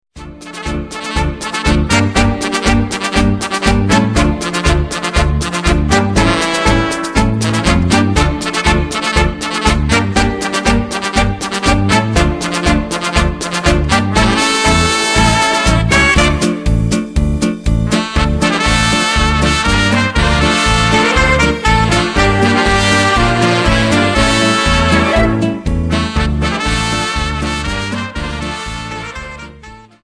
Dance: Paso Doble 60 Song